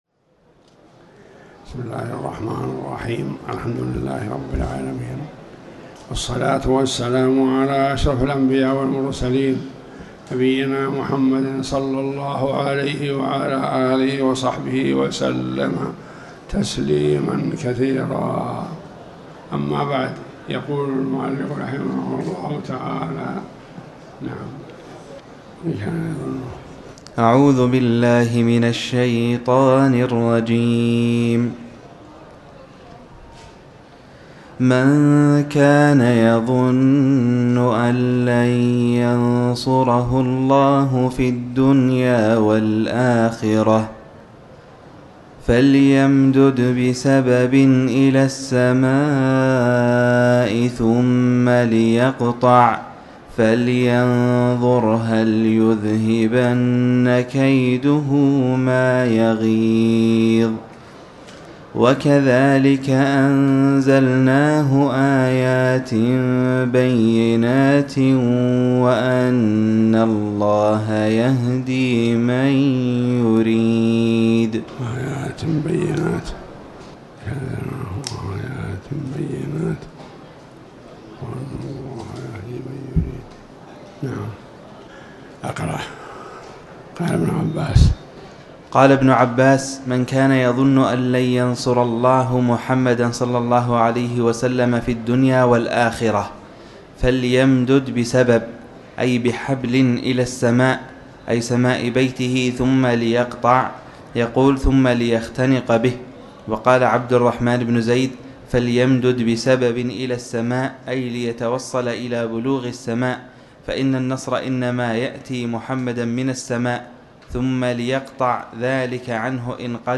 تاريخ النشر ٩ ذو القعدة ١٤٤٠ هـ المكان: المسجد الحرام الشيخ